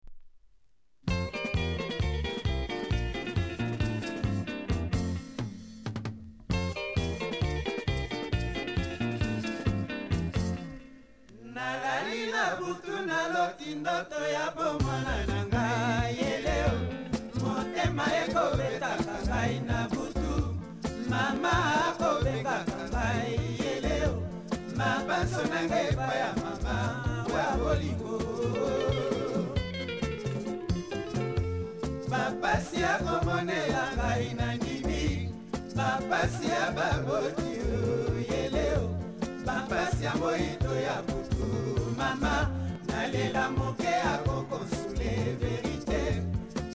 店舗 ただいま品切れ中です お気に入りに追加 コンゴ民主共和国からのsoukousバンド!